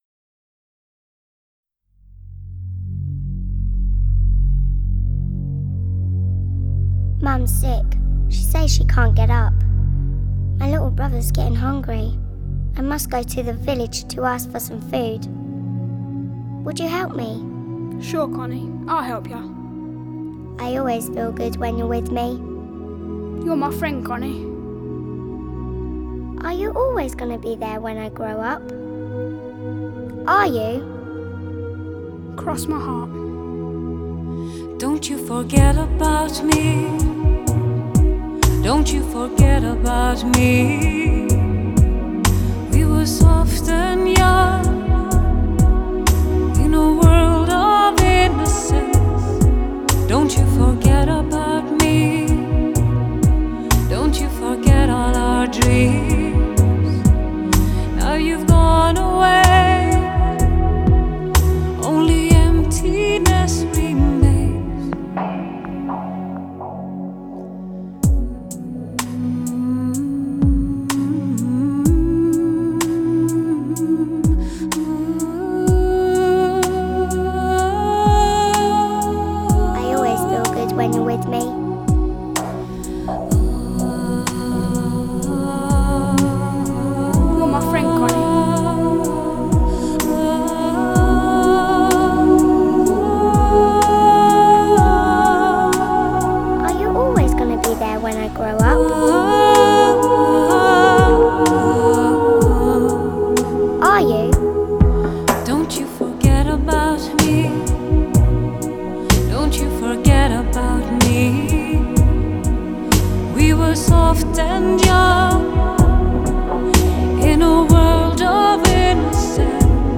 Genre : Ambient, Enigmatic, New Age